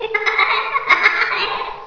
giggle.wav